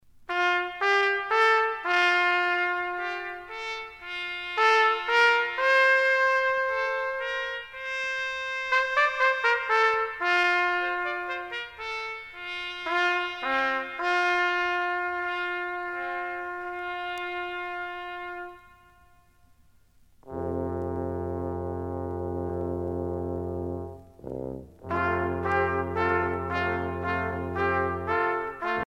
Chants brefs
Quintette de cuivres